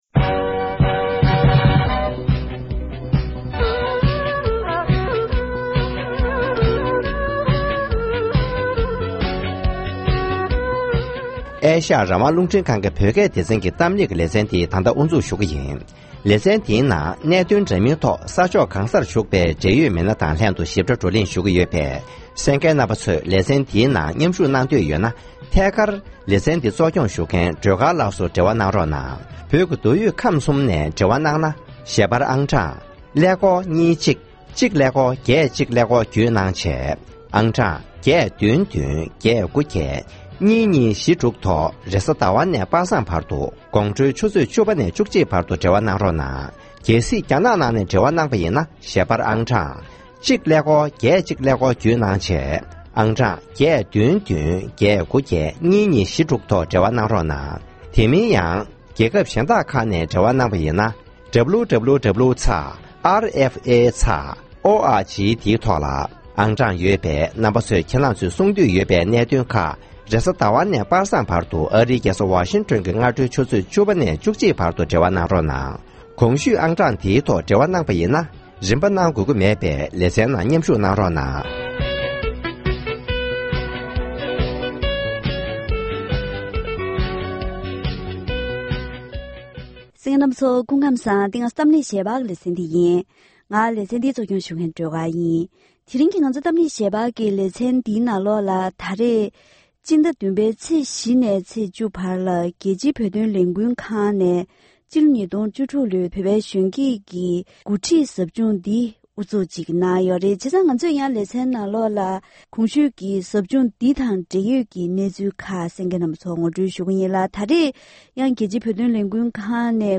༄༅། །ཐེངས་འདིའི་གཏམ་གླེང་ཞལ་པར་གྱི་ལེ་ཚན་ནང་རྒྱལ་སྤྱིའི་བོད་དོན་ལས་འགུལ་ཁང་ནས་གོ་སྒྲིག་གནང་བའི་སྤྱི་ལོ་༢༠༡༦ལོའི་བོད་པའི་གཞོན་སྐྱེས་ཀྱི་འགོ་ཁྲིད་ཟབ་སྦྱོང་ནང་མཉམ་ཞུགས་གནང་མཁན་ན་གཞོན་ཚོ་དང་ལྷན་འབྲེལ་ཡོད་ཟབ་སྦྱོང་སྐོར་ལ་བཀའ་མོལ་ཞུས་པ་ཞིག་གསན་རོགས་གནང་།